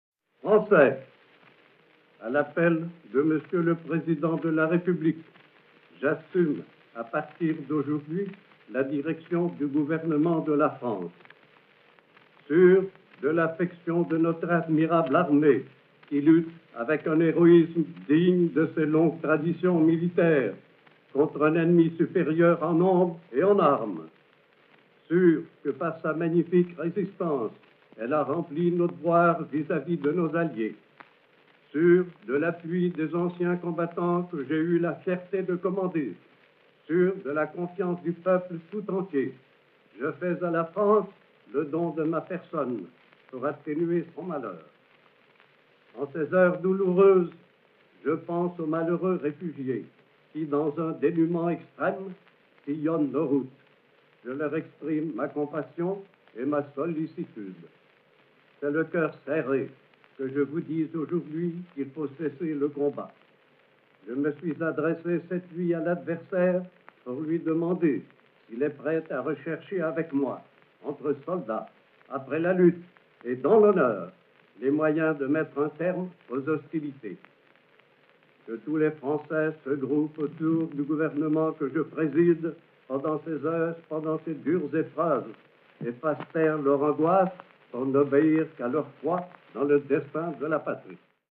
3---Discours-de-Petain-du-17-juin-1940.mp3